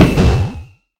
Minecraft Version Minecraft Version snapshot Latest Release | Latest Snapshot snapshot / assets / minecraft / sounds / mob / enderdragon / hit3.ogg Compare With Compare With Latest Release | Latest Snapshot